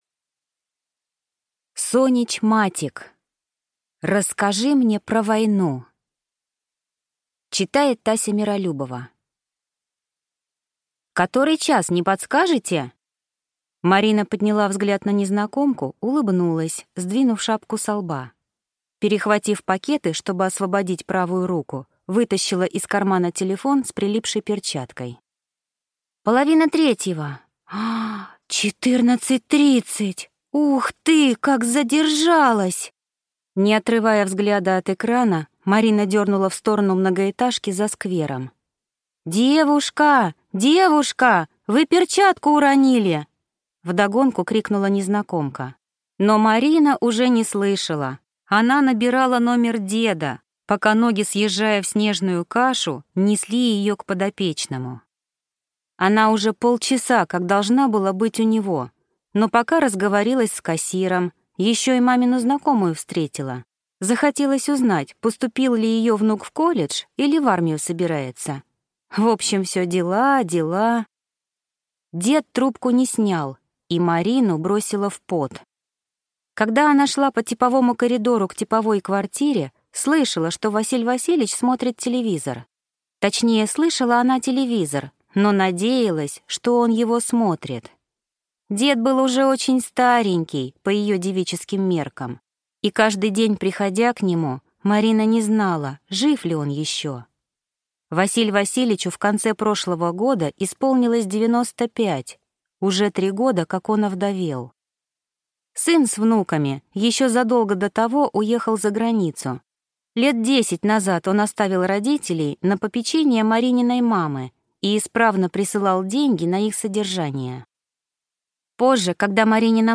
Аудиокнига Расскажи мне про войну | Библиотека аудиокниг